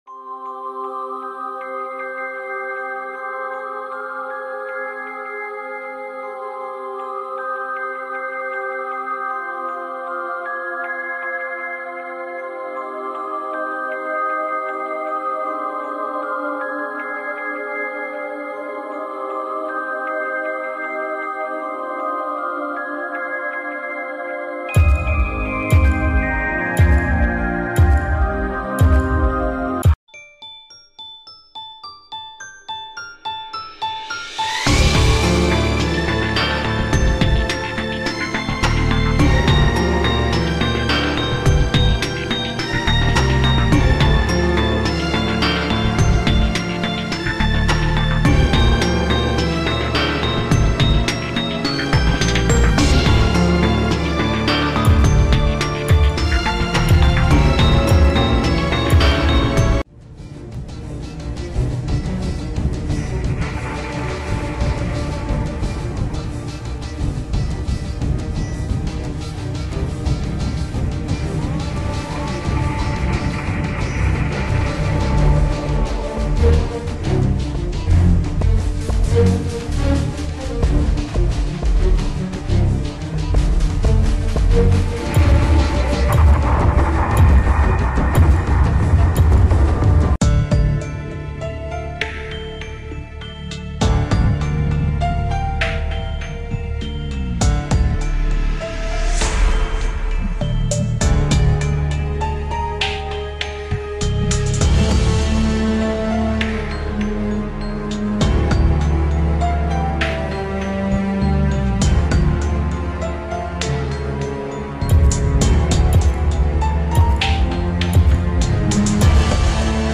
All Choosing Characters Audio